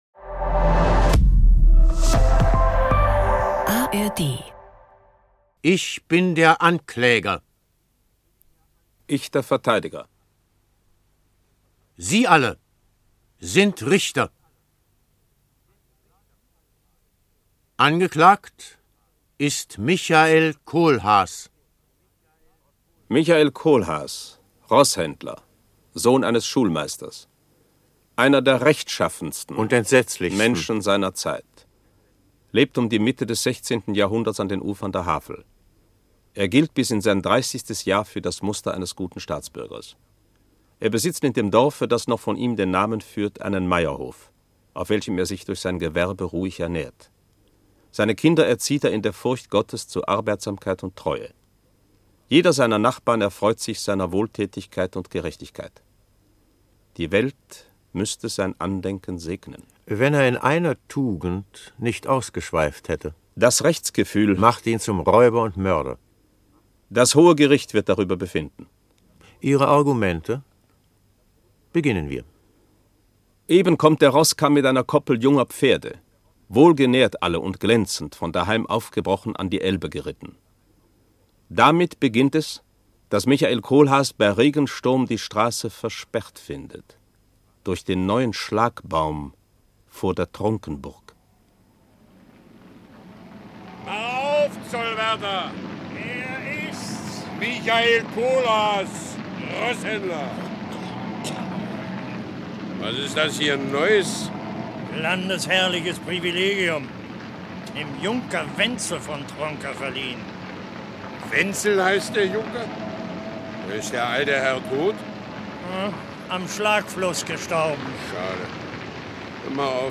… continue reading 510 Episoden # NDR # Audio Drama # Geschichtenerzählen